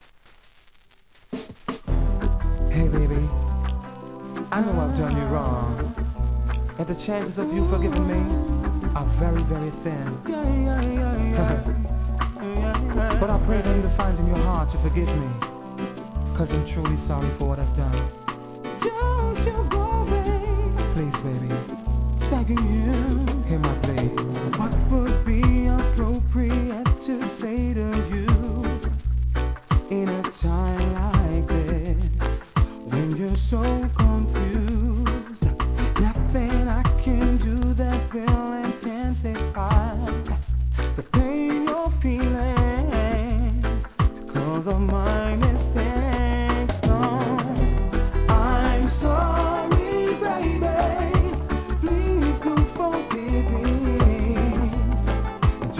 REGGAE
時代を変えたといっても過言ではないBIG HITミディアムRHYTHM!!